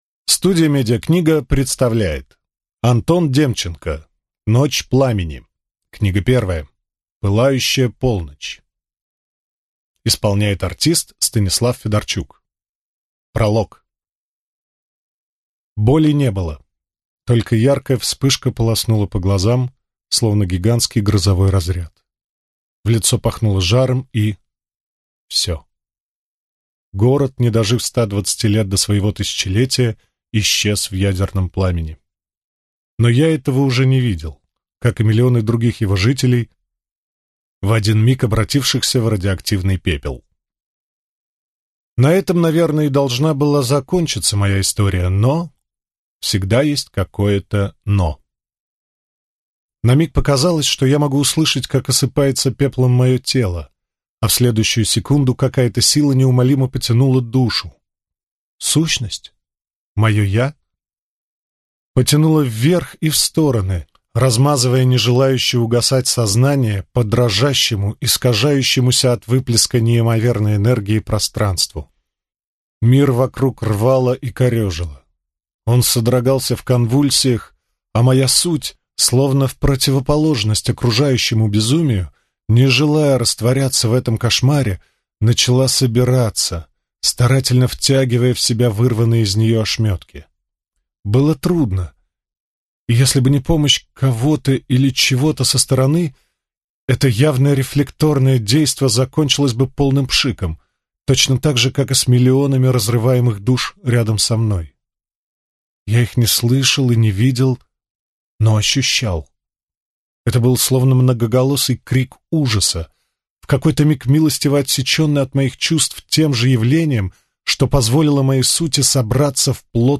Аудиокнига Пылающая полночь | Библиотека аудиокниг